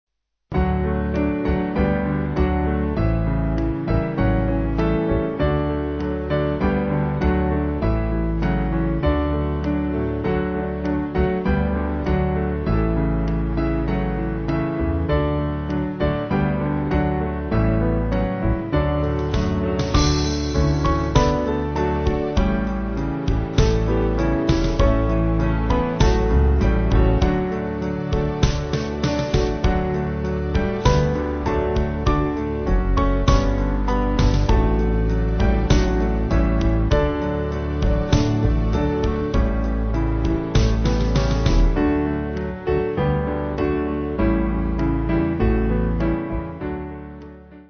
8.7.8.7. with refrain
Small Band